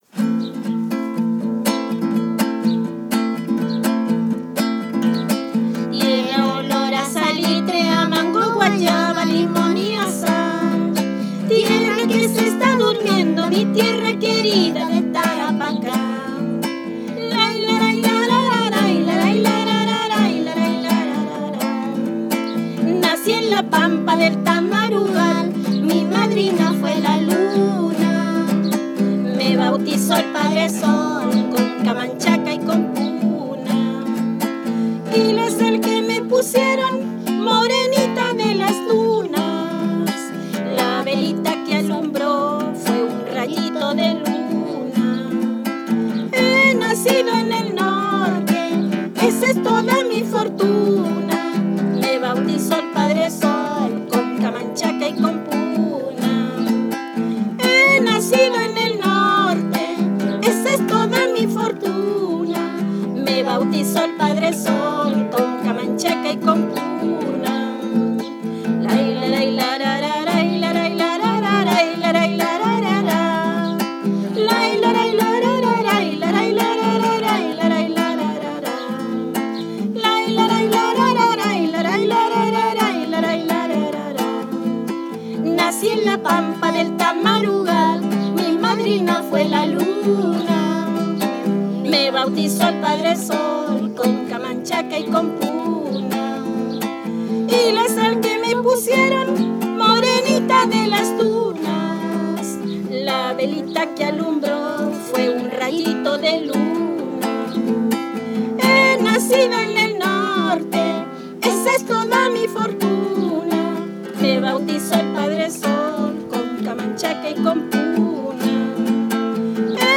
Música aymara (La Huayca, Tarapacá)
Música tradicional
Música vocal